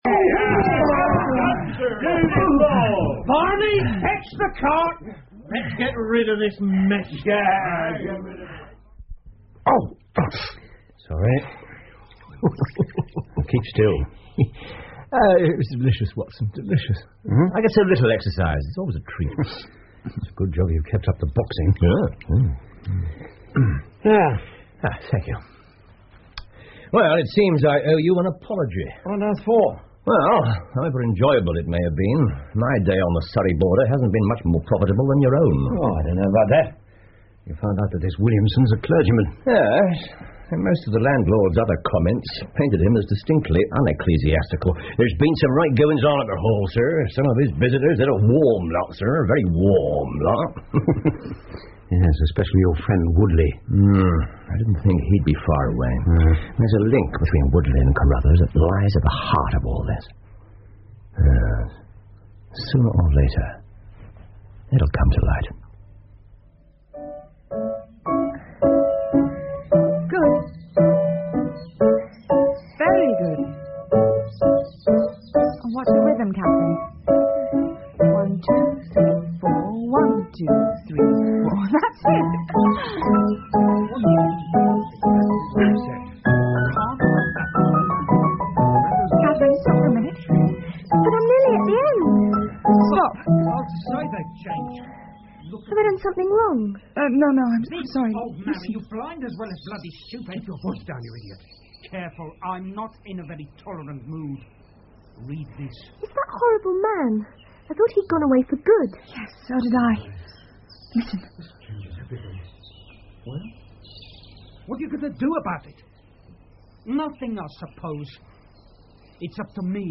福尔摩斯广播剧 The Solitary Cyclist 6 听力文件下载—在线英语听力室